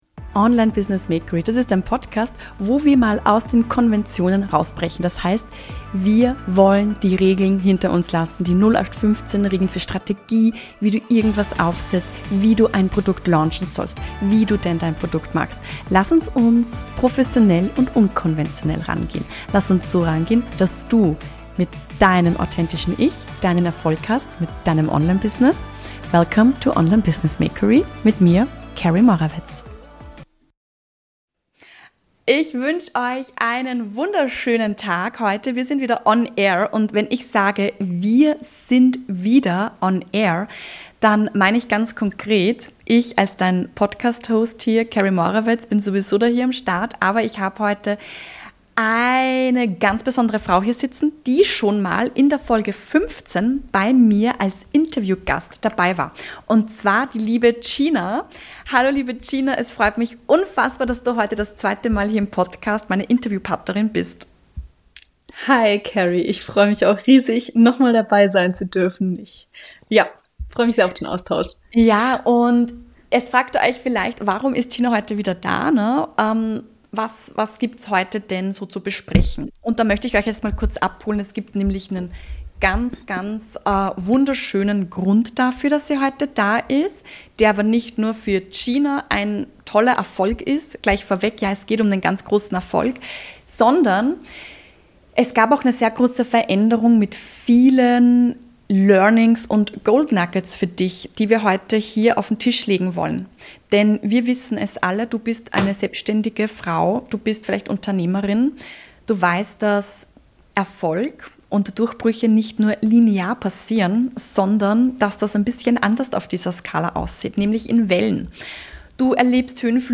#17 - Interview